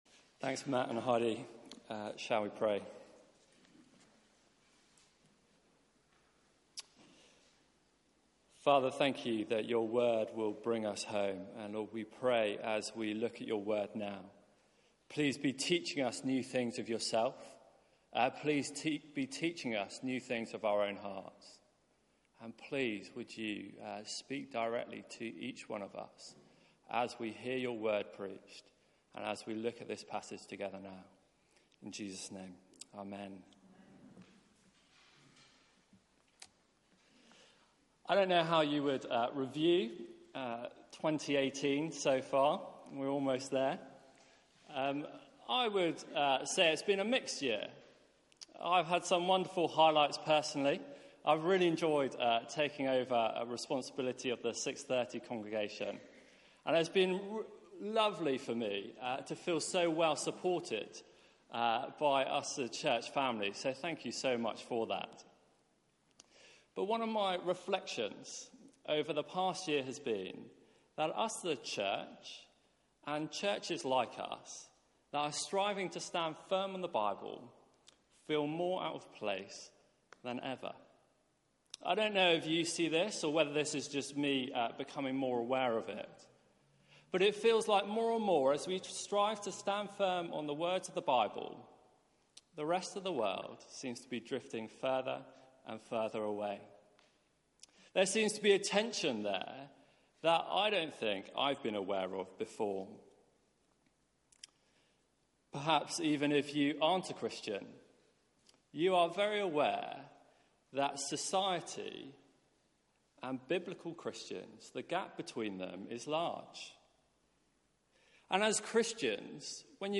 Media for 6:30pm Service on Sun 30th Dec 2018 18:30 Speaker